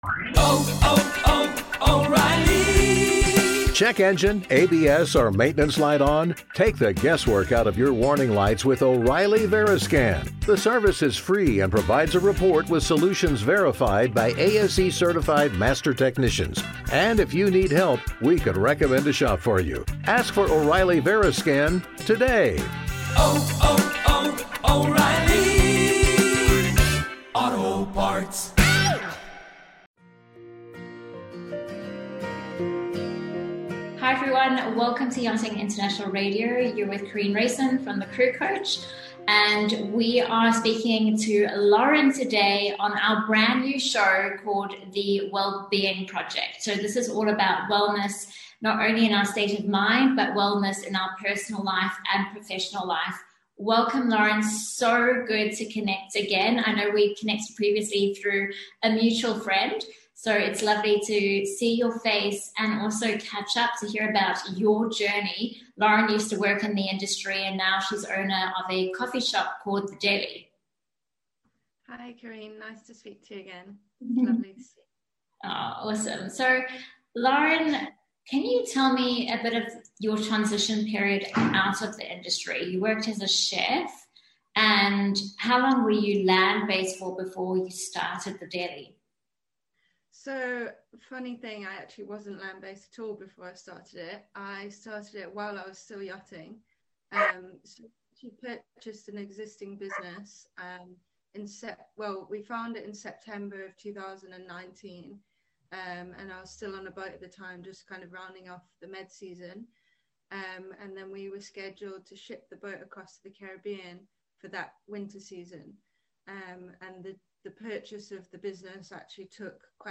A raw and honest discussion that will no doubt leave you feeling inspired.